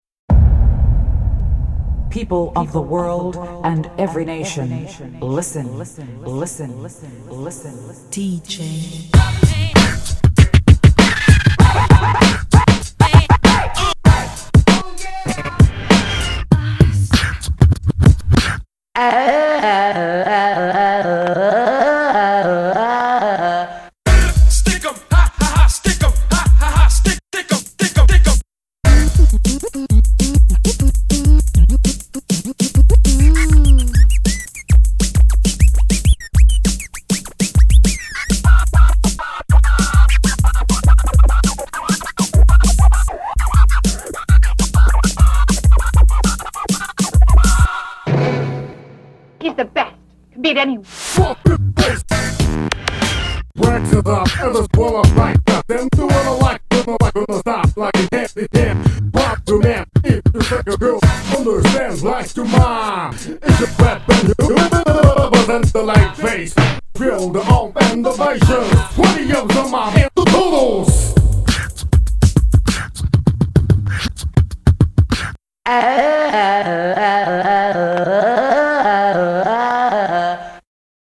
BPM97--1
Audio QualityPerfect (High Quality)